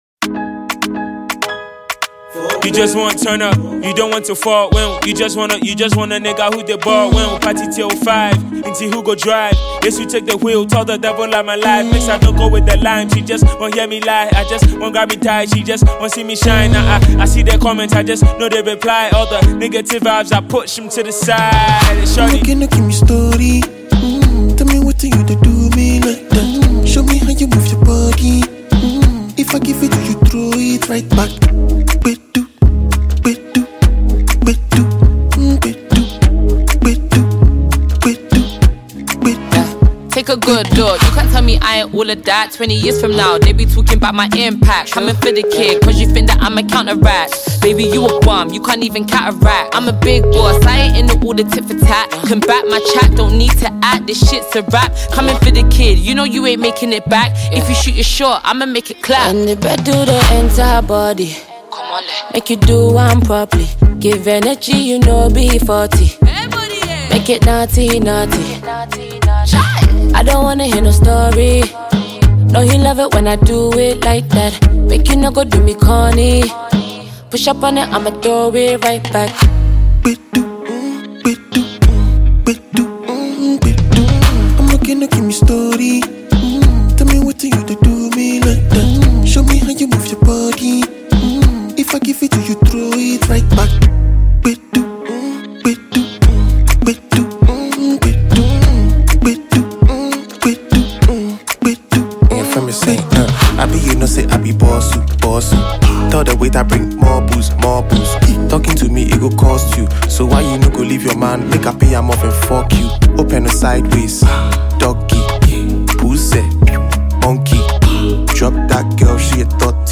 Ghana Music Music